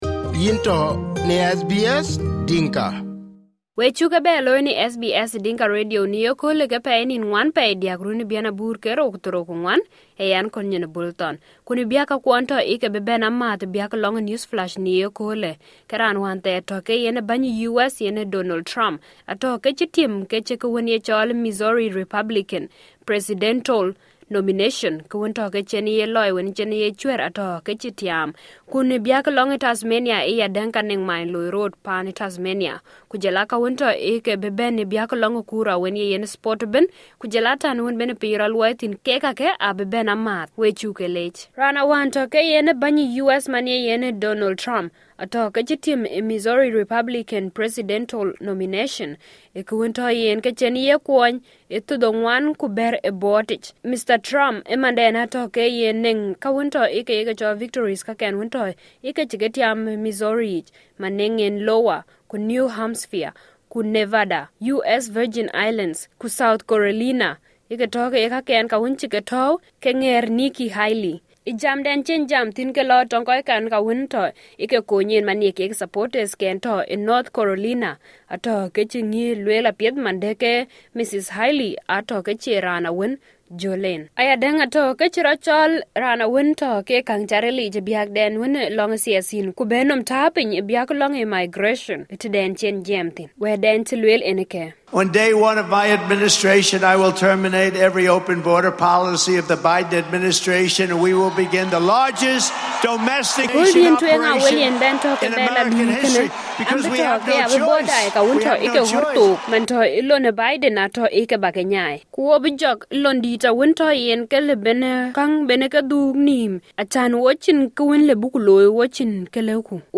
SBS Dinka News Flash 04/03/2024